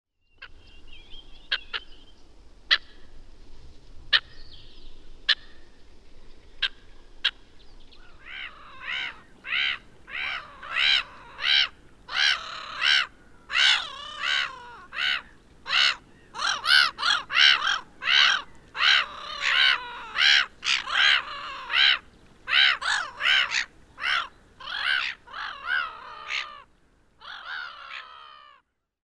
Kuuntele: Naurulokki